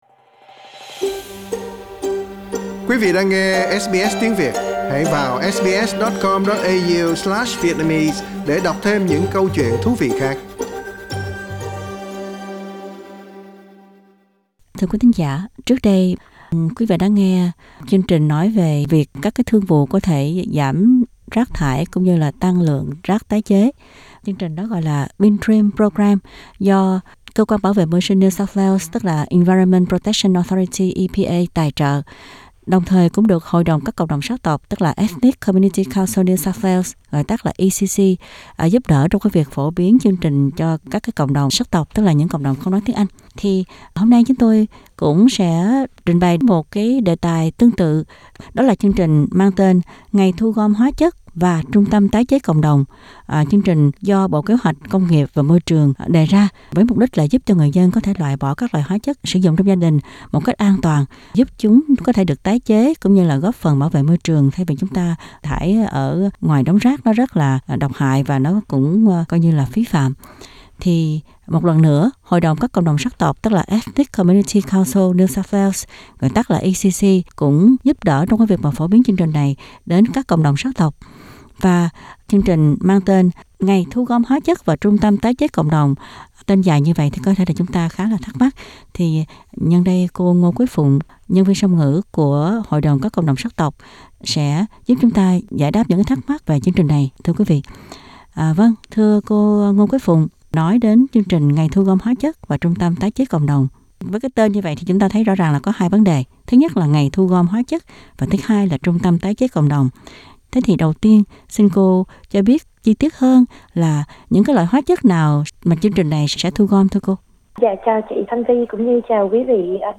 Trong phần phỏng vấn đầu trang